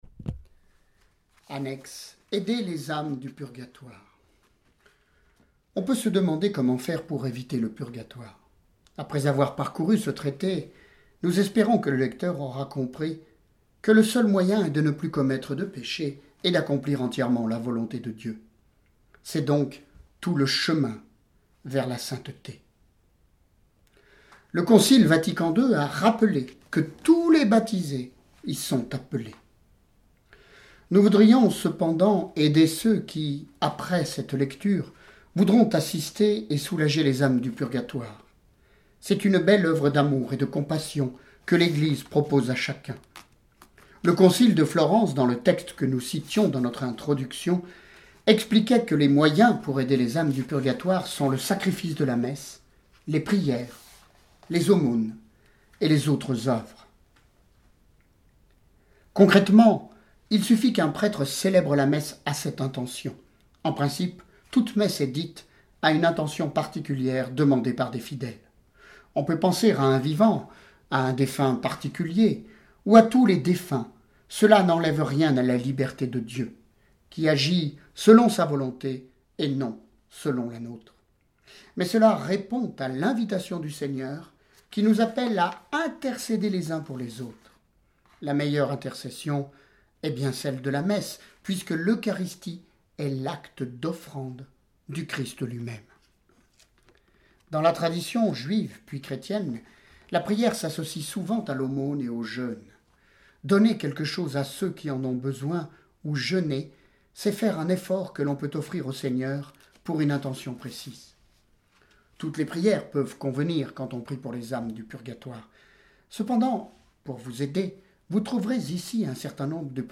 Histoires pour les enfants et pour ceux qui leur ressemblent >> Histoires de Saints et de Saintes >> Lecture de vie de Saints et Saintes >> Traité du Purgatoire >> 18e Chap. 18e Chap.